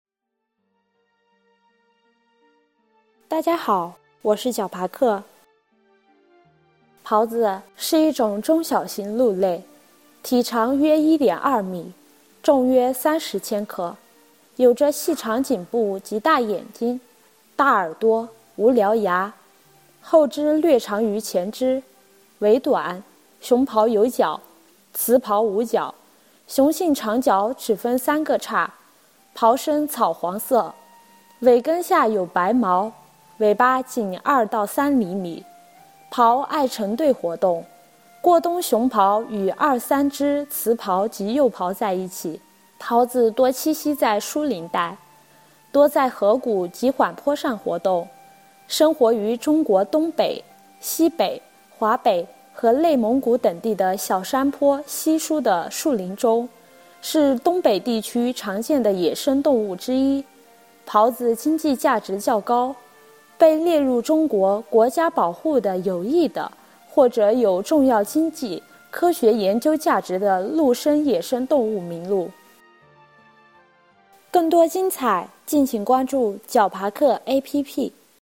解说词